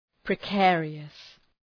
Shkrimi fonetik {prı’keərıəs}